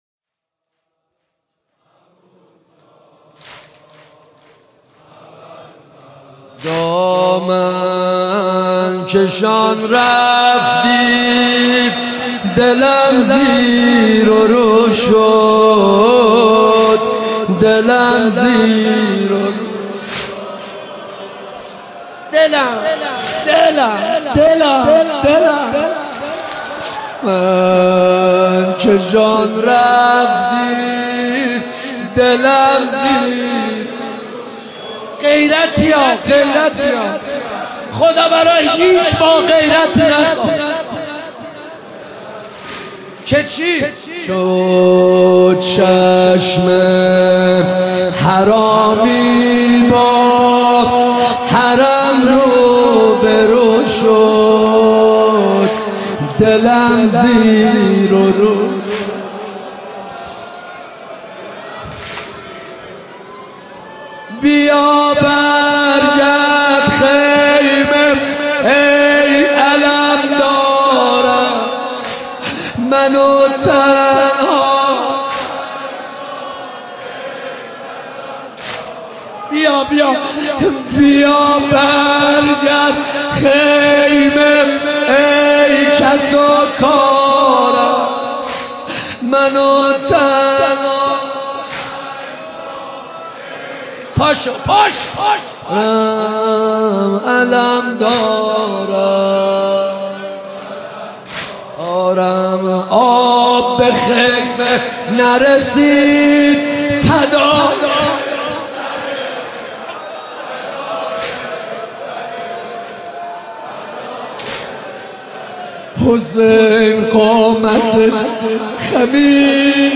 مداحی صوتی
نوحه جديد